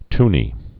(tnē)